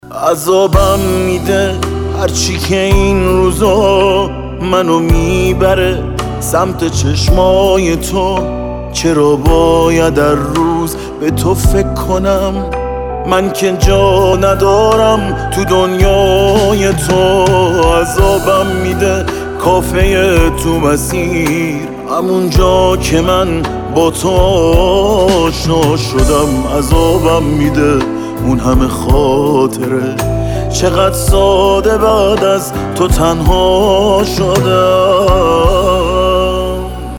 زنگ موبایل نیمه محزون